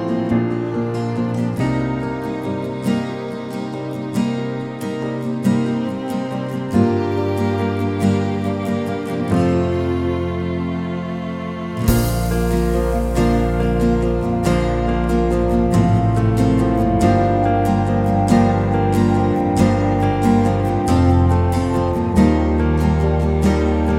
for Duet Pop (2010s) 4:43 Buy £1.50